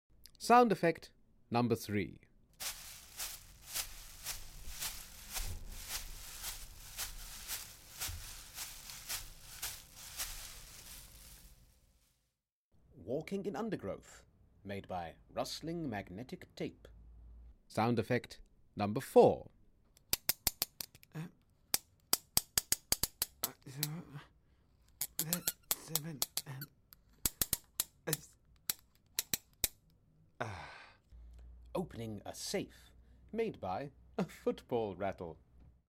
Dracula Sound Effects Quiz - Sound Effects 3 & 4 (ANSWERS)